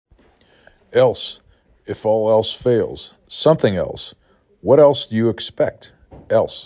4 Letters, 1 Syllable
3 Phonemes
e l s